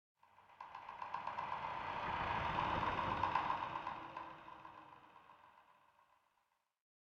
ambienturban_23.ogg